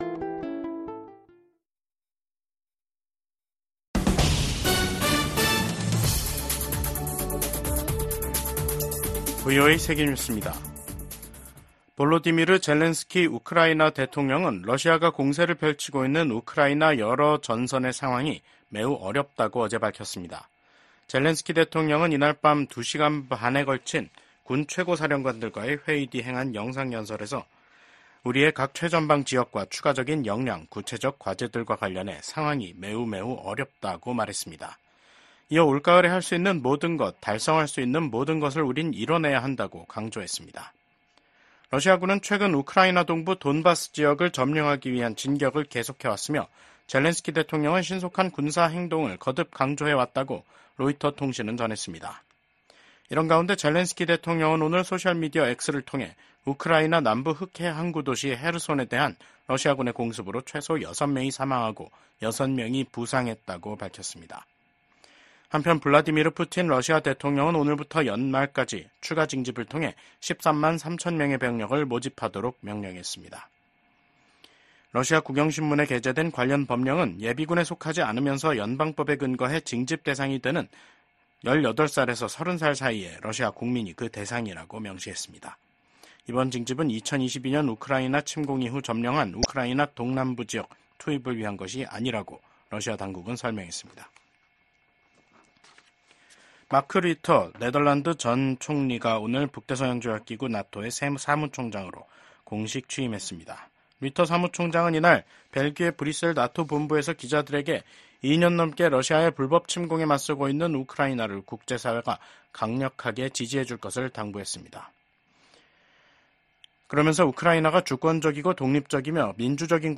VOA 한국어 간판 뉴스 프로그램 '뉴스 투데이', 2024년 10월 1일 2부 방송입니다. 윤석열 한국 대통령은 북한이 핵무기를 사용하려 한다면 정권 종말을 맞게 될 것이라고 경고했습니다. 김성 유엔주재 북한 대사가 북한의 핵무기는 자위권을 위한 수단이며 미국과 핵 문제를 놓고 협상하지 않겠다고 밝혔습니다.